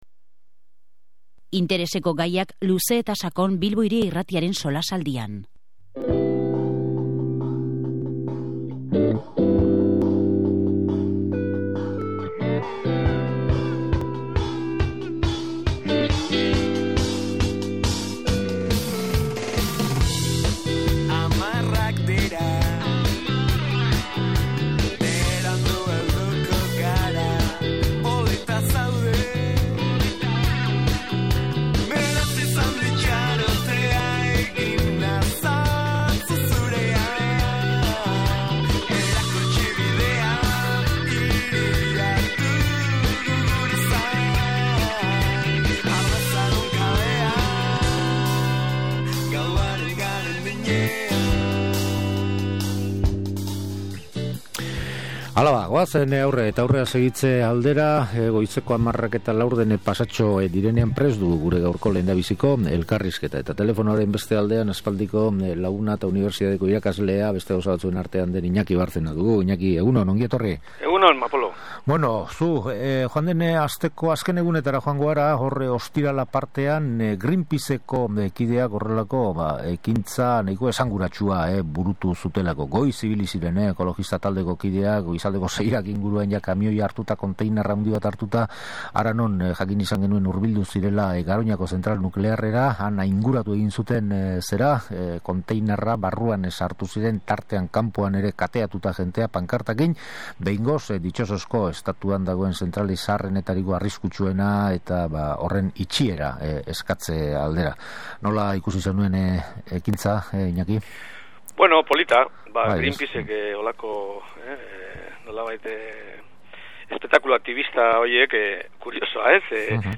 SOLASALDIA: Garoñako zentral nuklearra
solasaldia